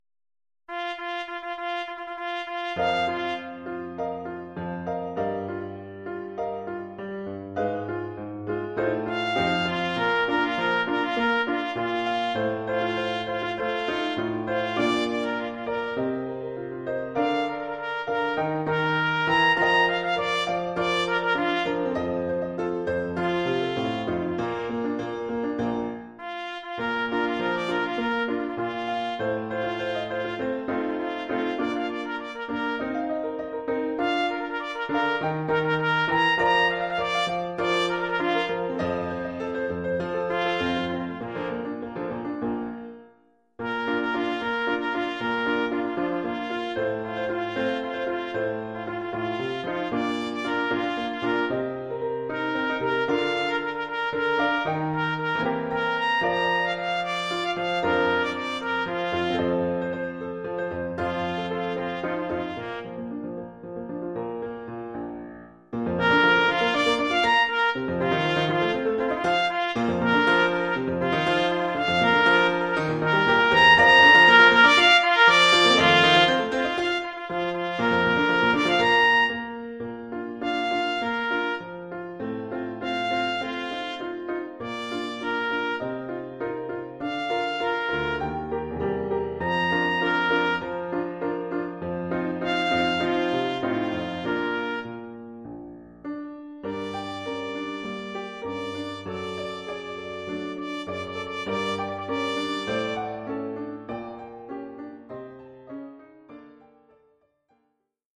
Oeuvre pour clairon sib et piano.